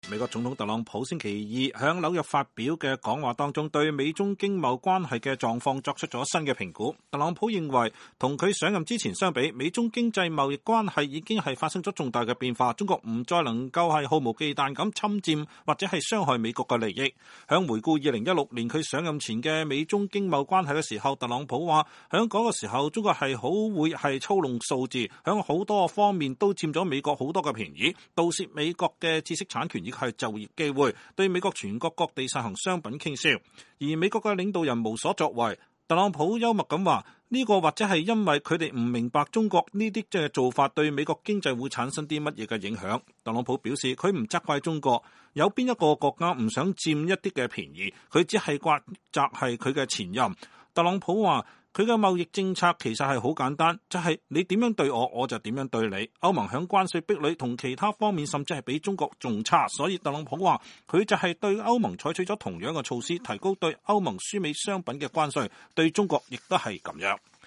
美國總統特朗普週二（11月12日）在紐約發表的講話中對美中經貿關係的狀況做出了新的評估。特朗普認為，與他上任之前相比，美中經貿關係已經發生了重大變化，中國不再能夠毫無忌憚的侵占或者傷害美國的利益。